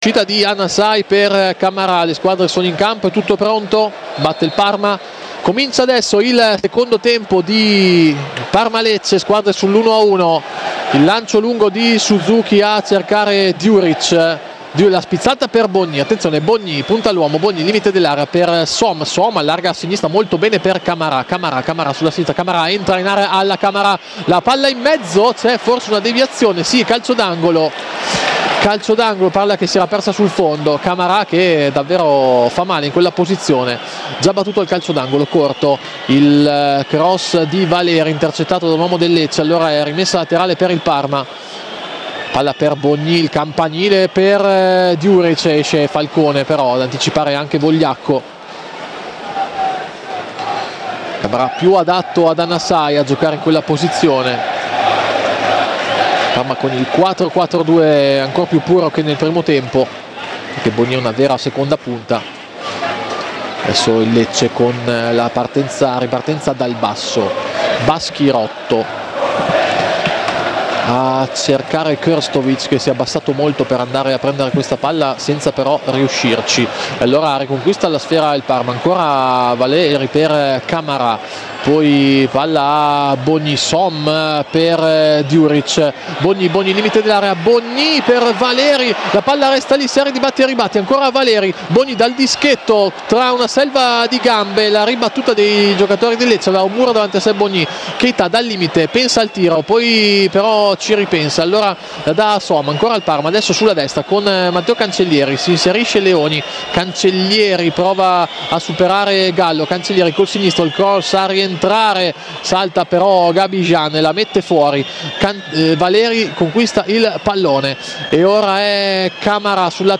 Radiocronaca